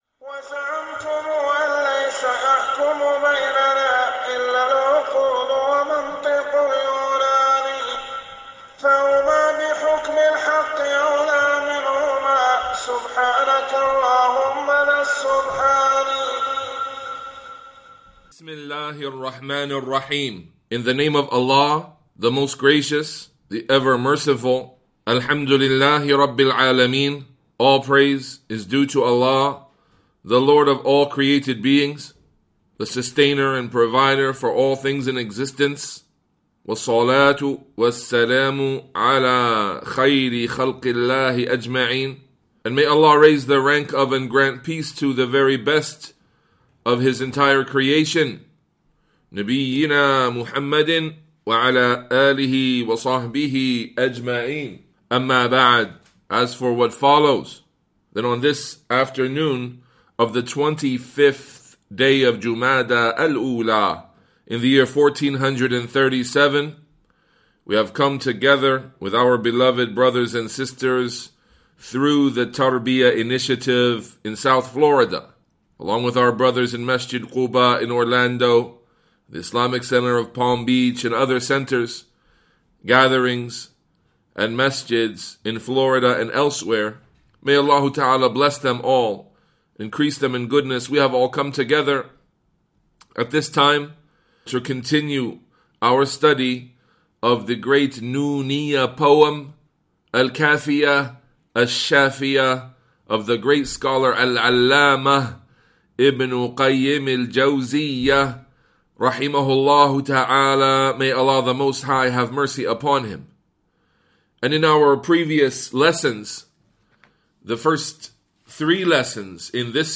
Nooniyyah Poem Class Recordings